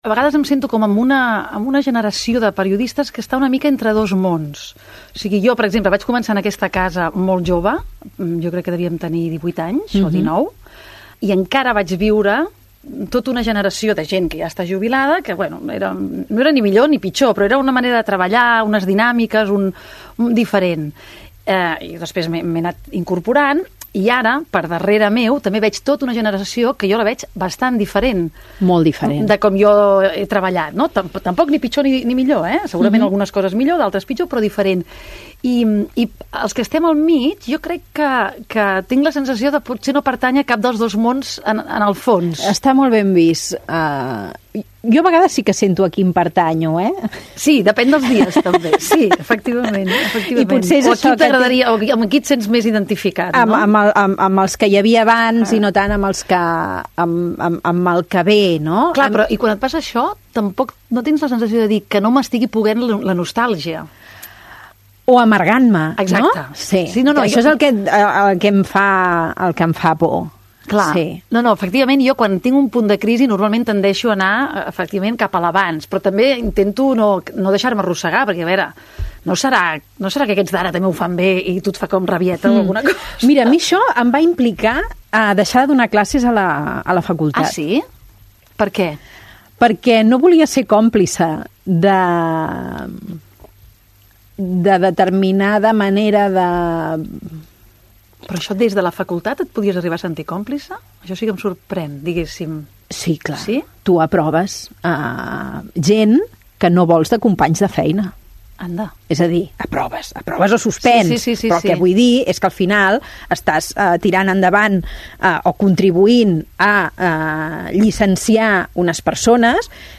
Llàpis de memòria: Conversa